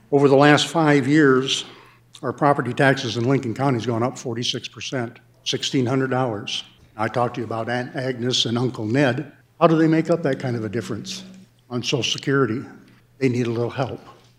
Sen. Ernie Otten of Tea carried the bill on the floor and says property owners in fast-growing counties have it bad.